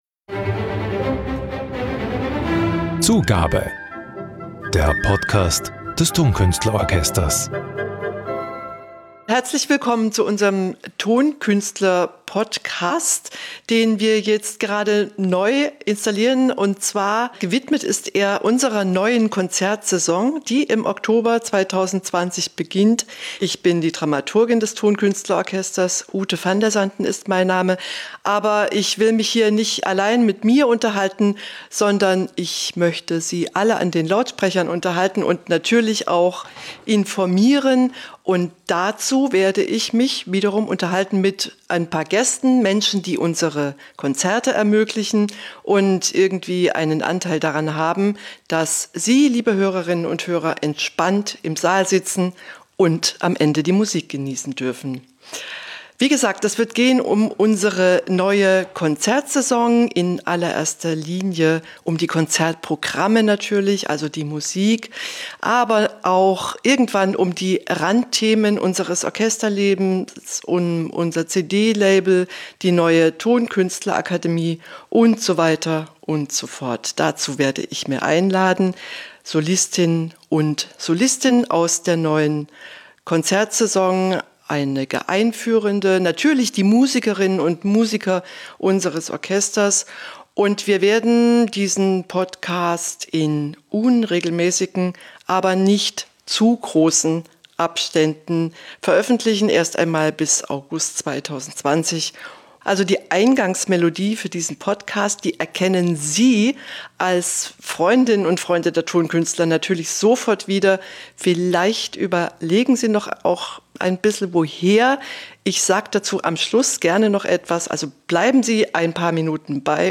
im Gespräch mit ihren Gästen